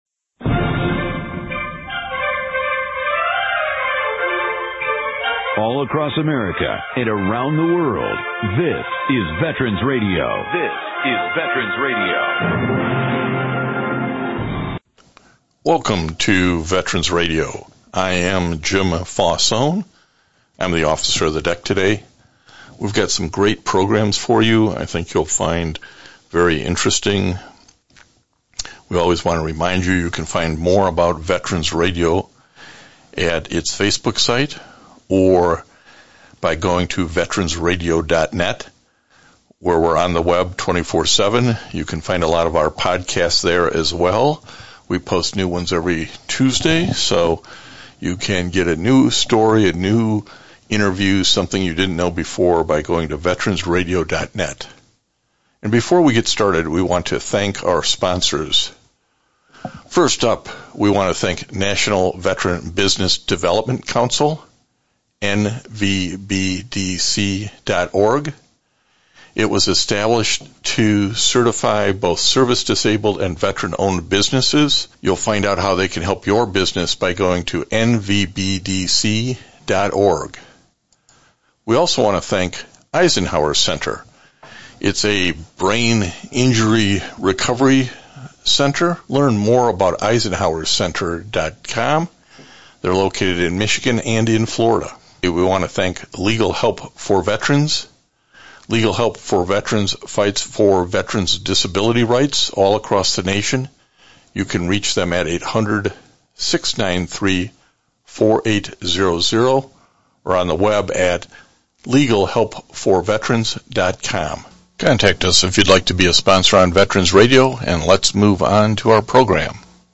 HEAR VETERANS RADIO ANYWHERE Stay connected with the stories, voices, and history that matter.